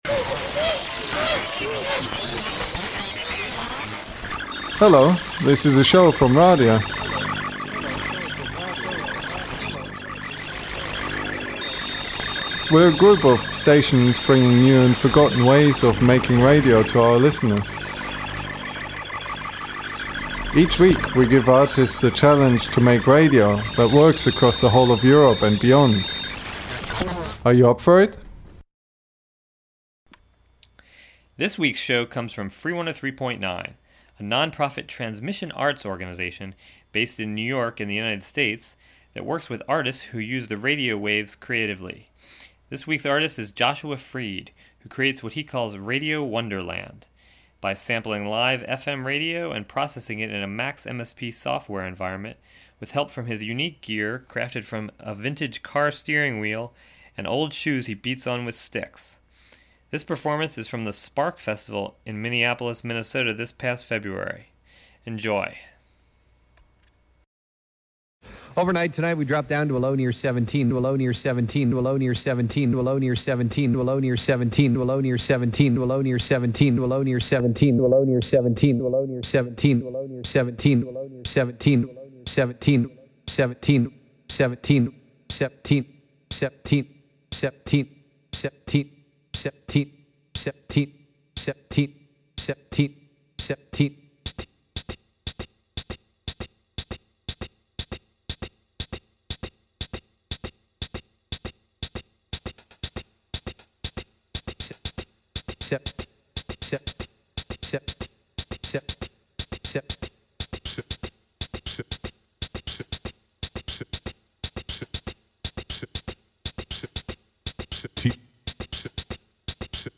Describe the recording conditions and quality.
The 35-minute raw material was condensed and gently remixed